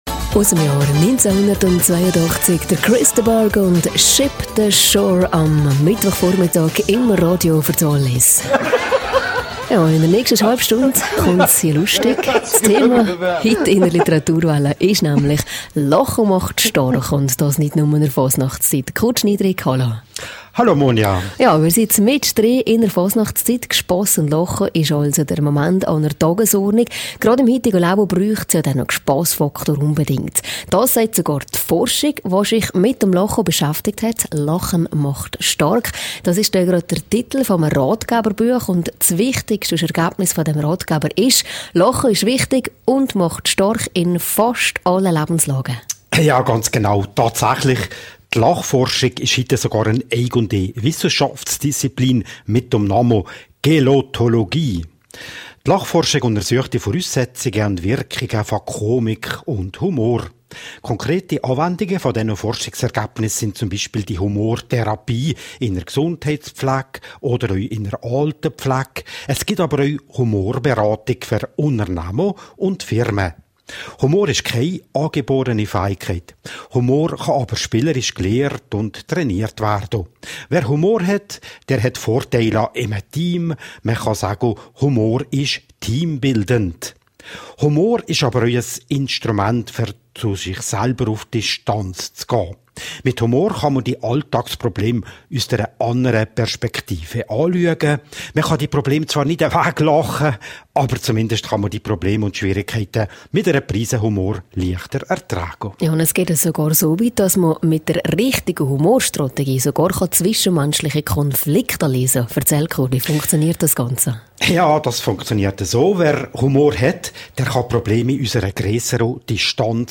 Moderation: